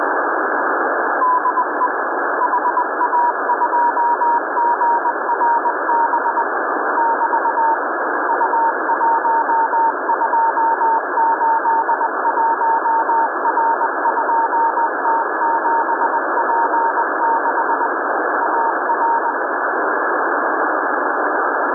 GRBBeta CW beacon was heard here in Brazil… on its side was the ROBUSTA-3A Beacon
HERE I make the CW audio of GRBBeta available
Unfortunately, I don’t think I’ll be able to extract anything from GRBBeta’s 9k6 packets because their signal was too weak here for me